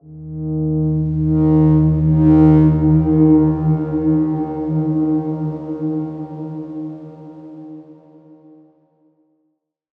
X_Darkswarm-D#2-mf.wav